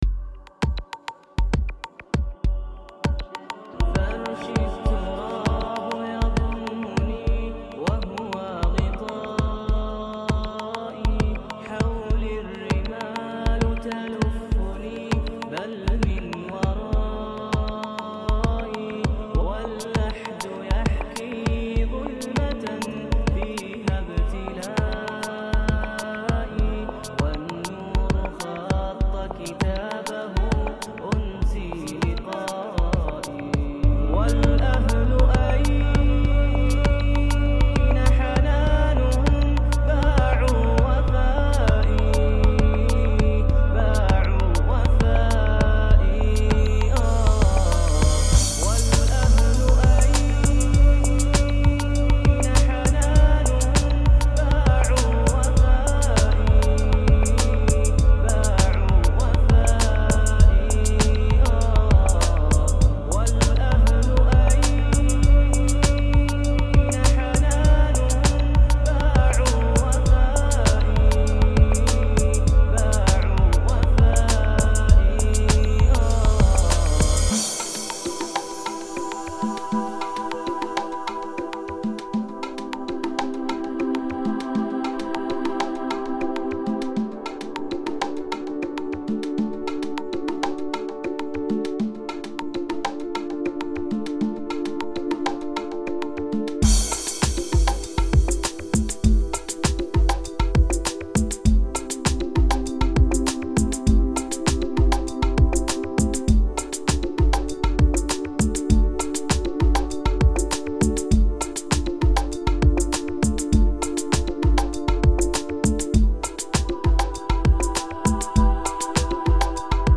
24 Восточная сказка только для вас!!!!